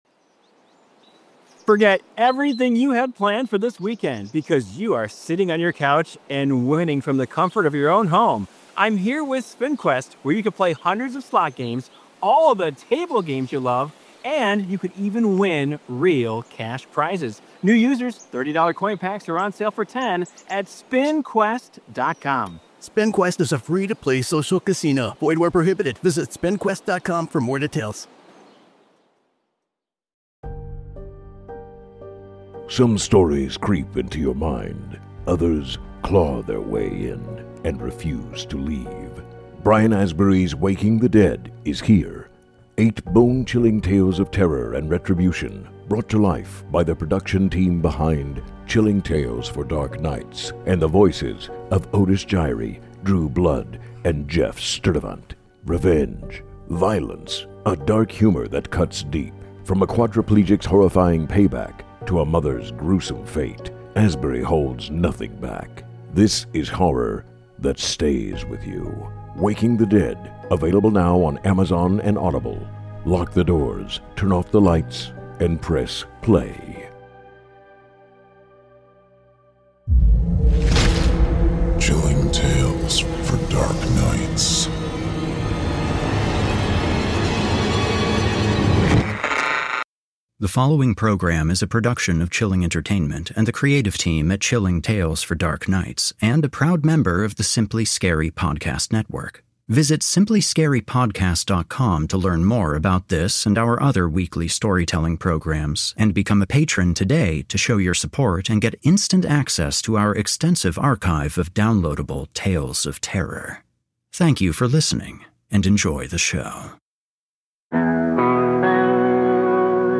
Horror Hill: A Horror Anthology and Scary Stories Series Podcast / S12E07 - "Domestic Dualities" - Horror Hill